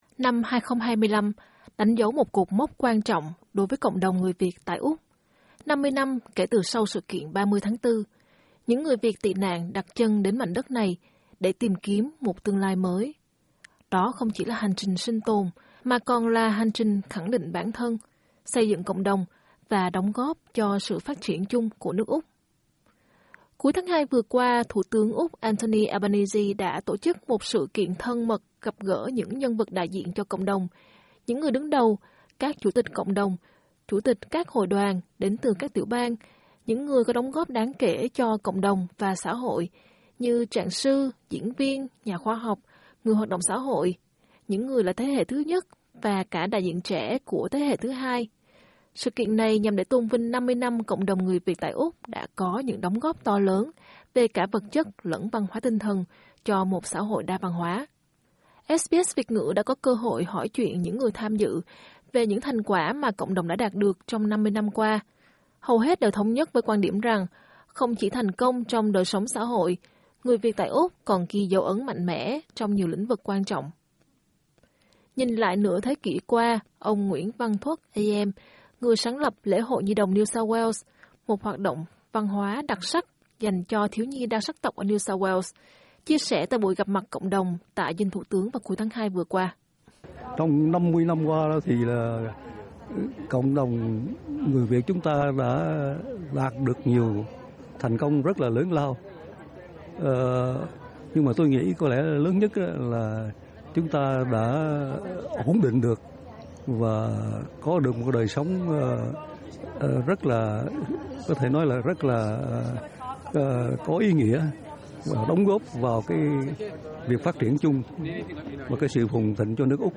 LISTEN TO 50 năm nhìn lại: Câu chuyện về sự sống còn, thành công và bản sắc người Việt ở Úc SBS Vietnamese 06:05 English SBS Việt ngữ đã có cơ hội hỏi chuyện những người tham dự về những thành quả mà cộng đồng đã đạt được trong 50 năm qua.